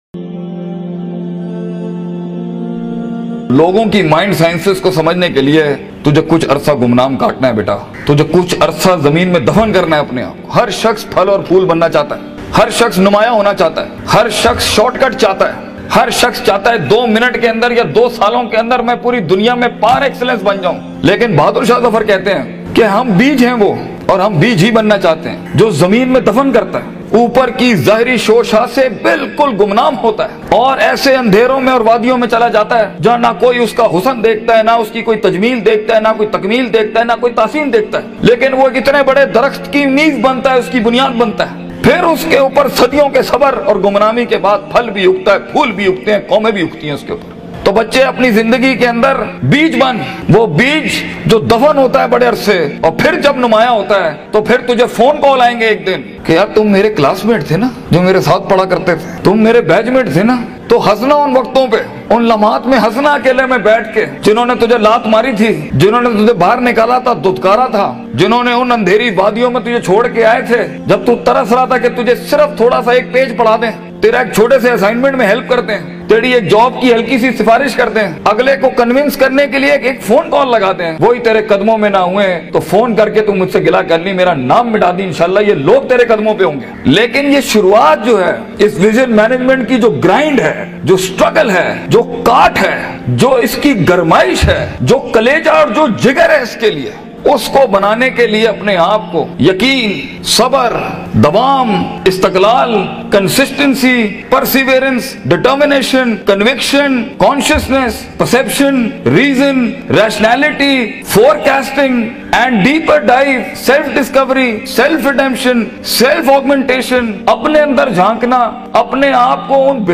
Gumnami ka Safar Corporate Motivational Session Al Midrar Institute.mp3